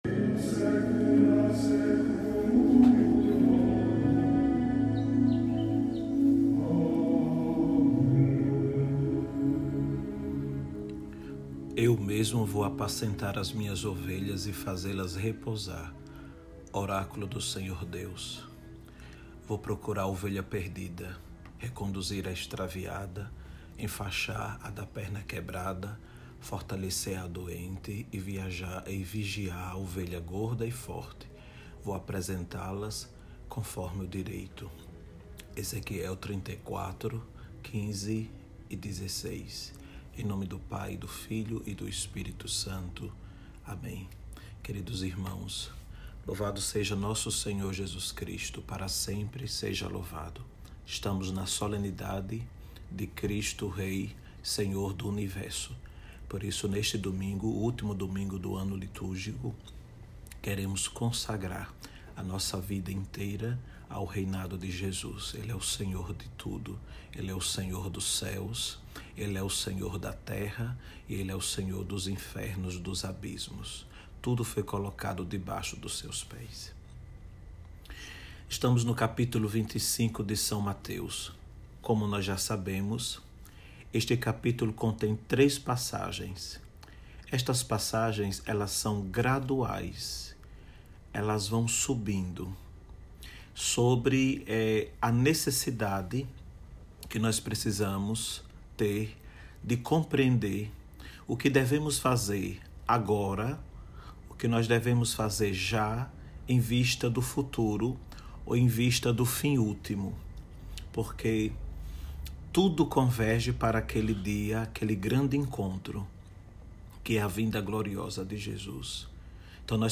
Homilia - Solenidade de Cristo, Rei do Universo